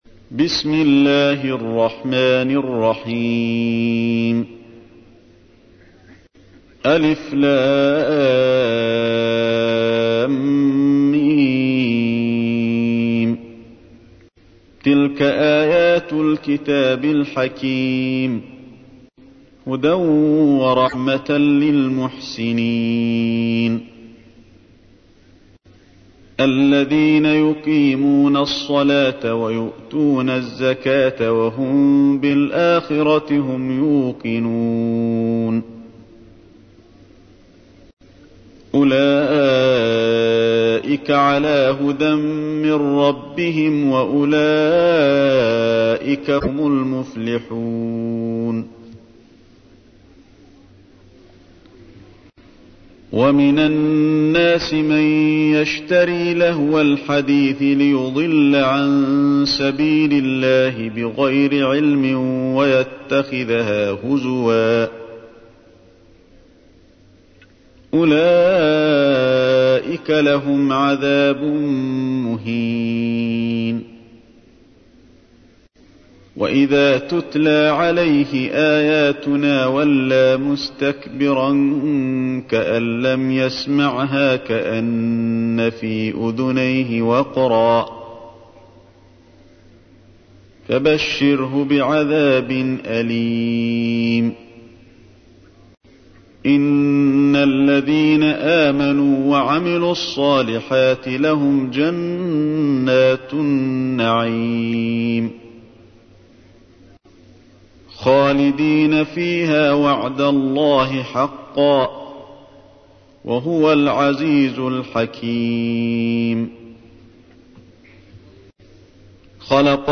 تحميل : 31. سورة لقمان / القارئ علي الحذيفي / القرآن الكريم / موقع يا حسين